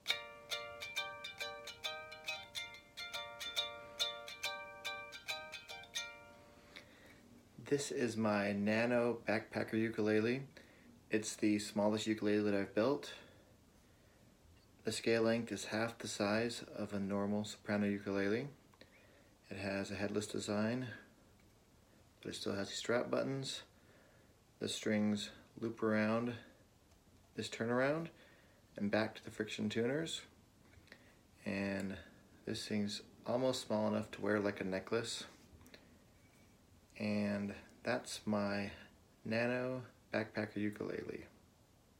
Demo of my Nano Travel Ukulele.